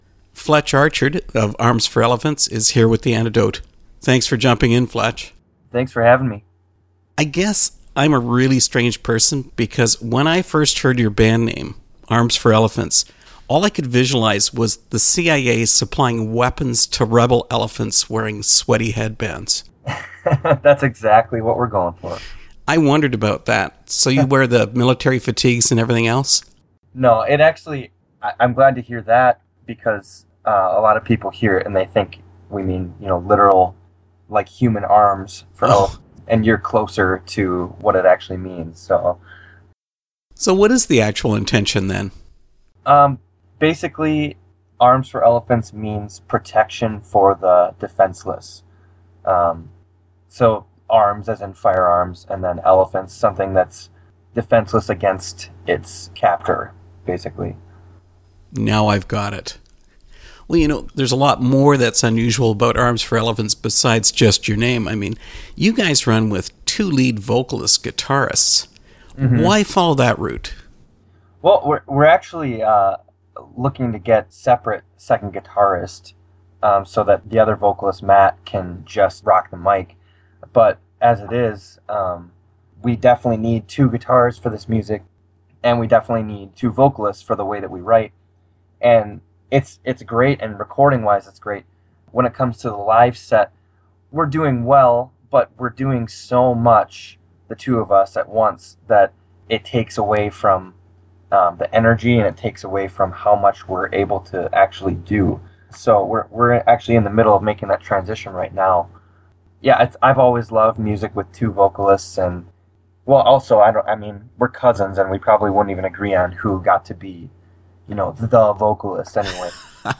Interview with Arms For Elephants
arms-for-elephants-interview.mp3